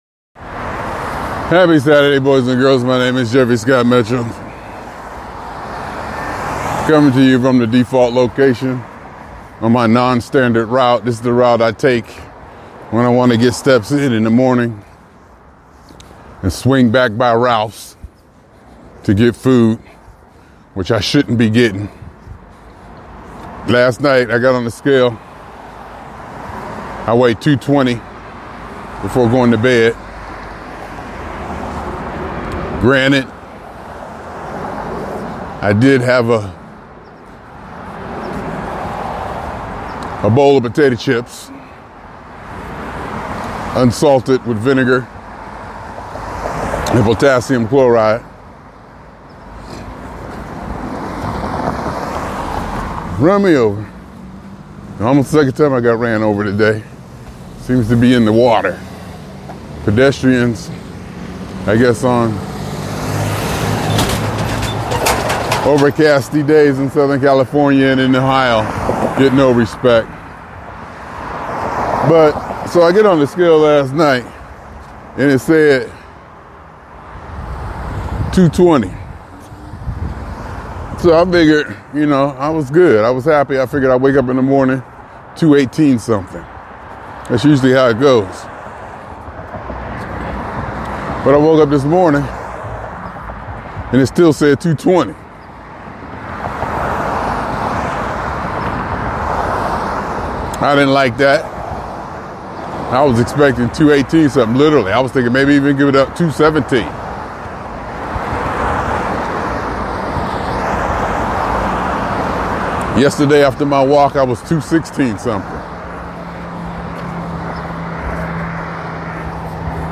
during a walk in Southern California